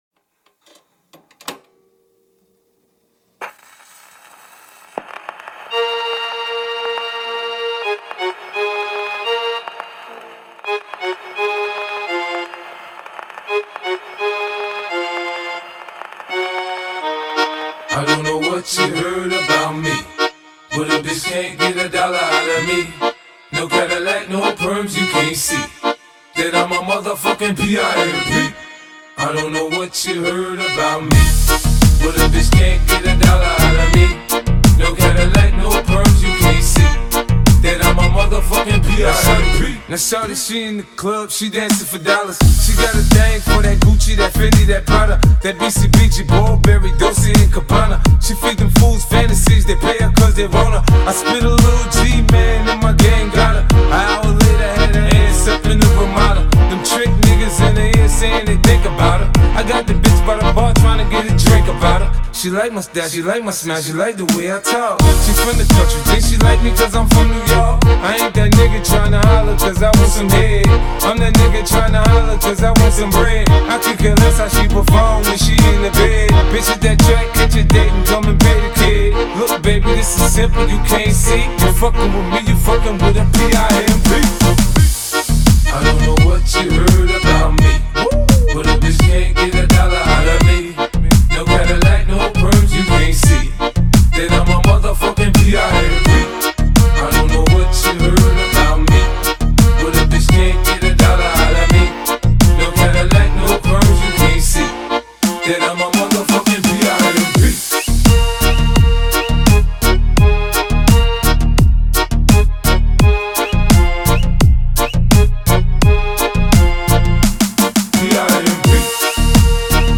Акордеон Ремікс з ТікТок